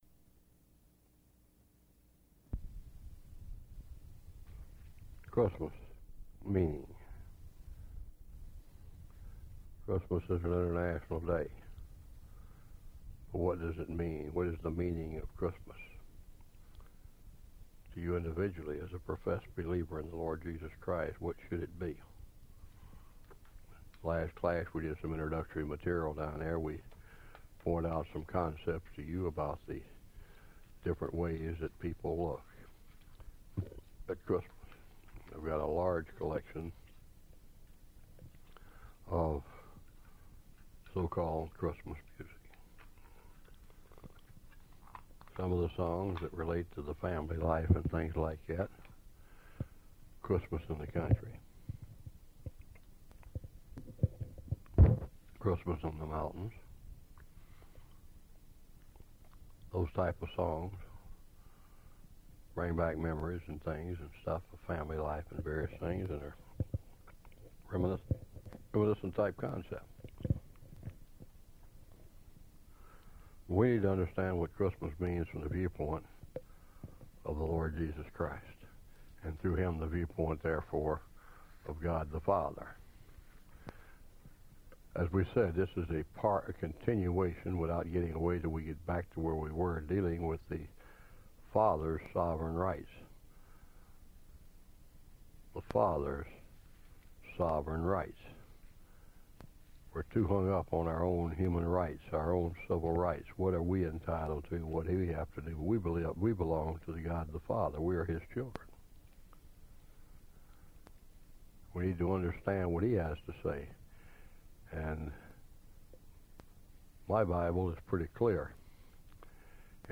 Christmas Meaning Bible Study Series